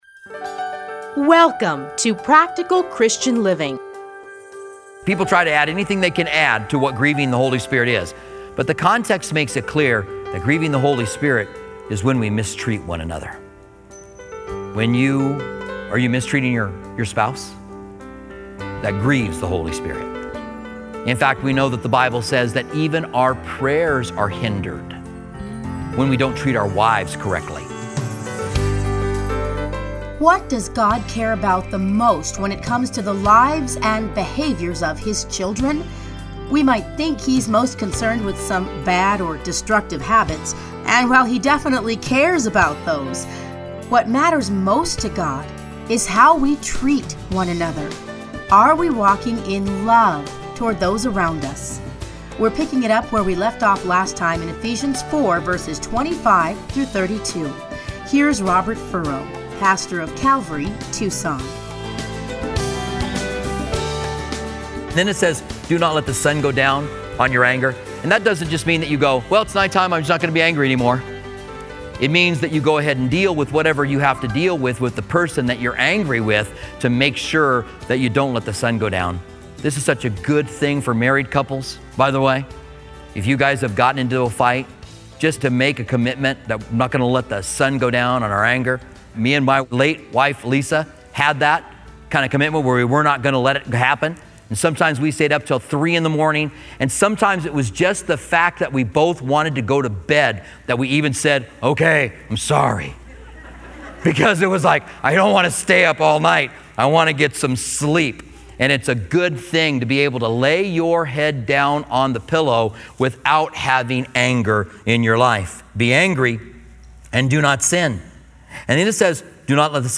Listen here to his commentary on Ephesians.